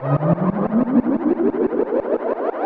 Index of /musicradar/rhythmic-inspiration-samples/90bpm
RI_ArpegiFex_90-02.wav